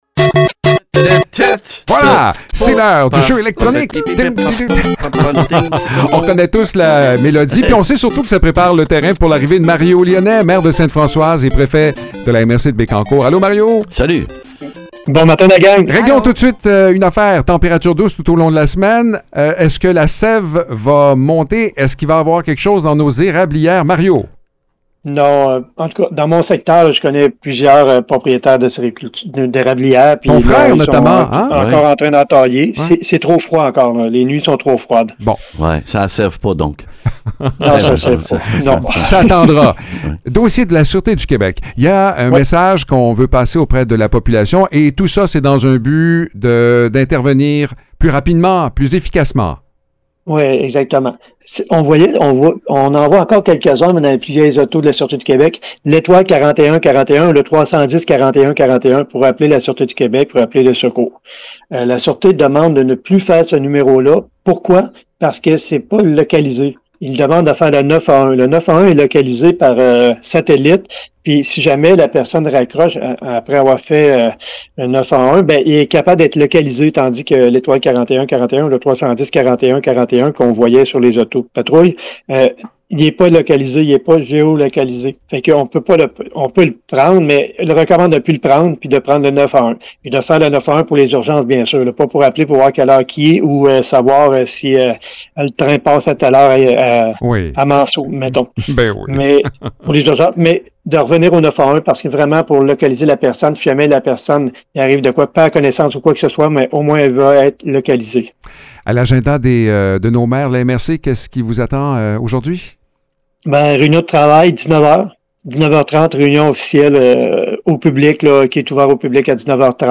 Mario Lyonnais, maire de Sainte-Françoise et préfet de la MRC de Bécancour, nous dit pourquoi il est mieux de composer le 9-1-1, en situation d’urgence, plutôt que les anciens numéros administratifs.